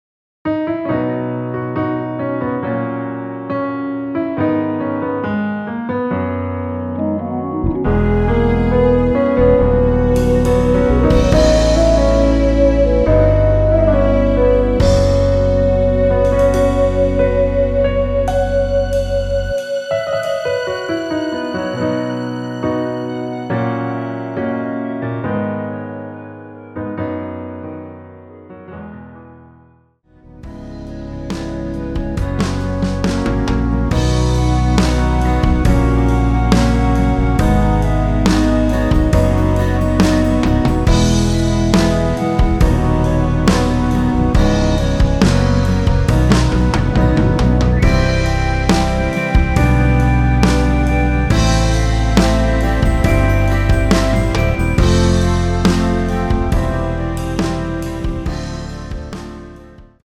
(-3) 내린 멜로디 포함된 MR 입니다.(미리듣기 참조)
남성분들이 부르실수 있는 키로 제작 되었습니다.
앞부분30초, 뒷부분30초씩 편집해서 올려 드리고 있습니다.
중간에 음이 끈어지고 다시 나오는 이유는